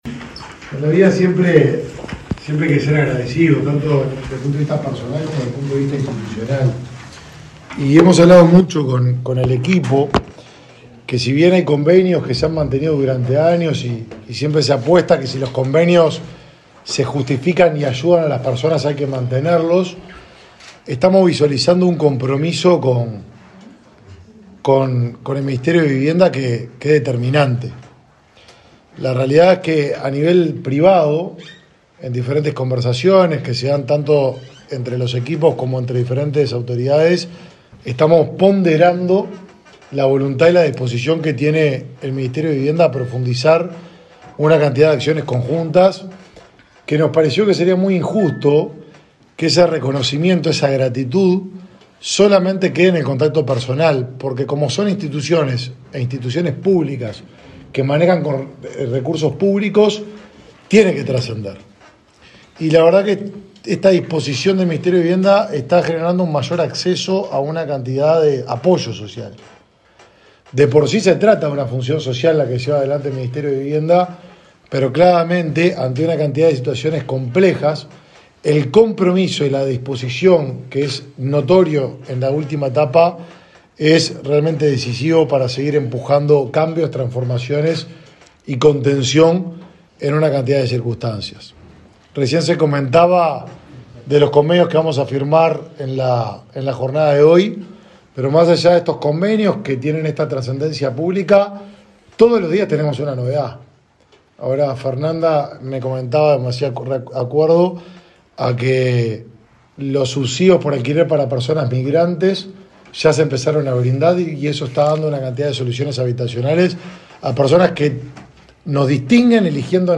Palabra de autoridades en convenio entre el Ministerio de Vivienda y el de Desarrollo Social